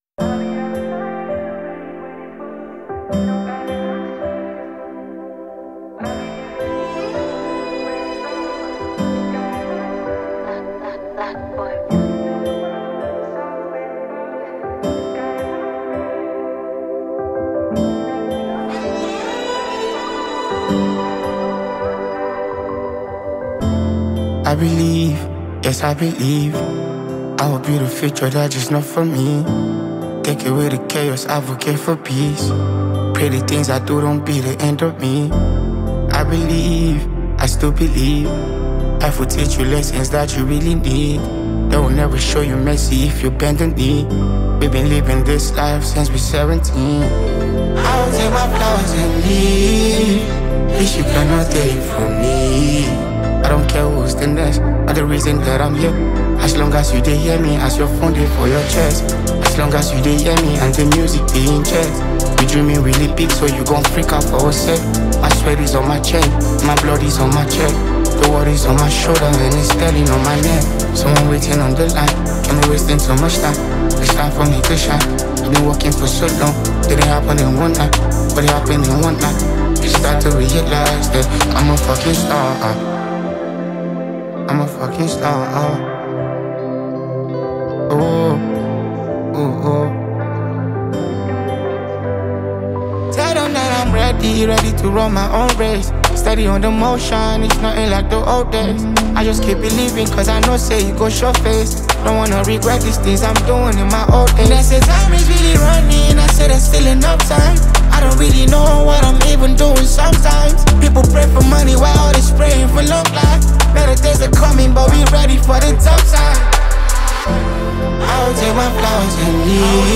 an emotionally charged new single
wrapped in smooth vocals and captivating production.